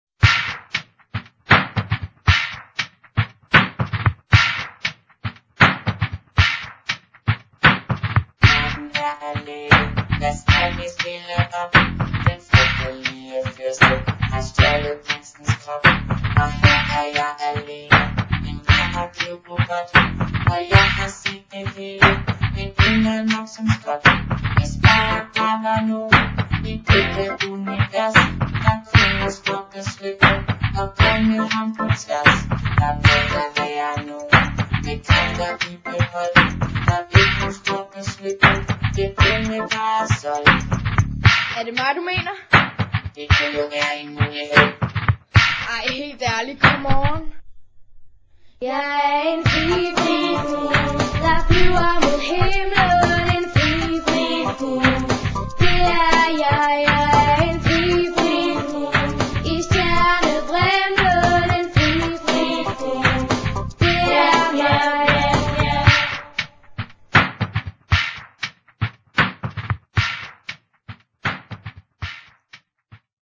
Lutter sangbare og ørehængende slagere finder man her!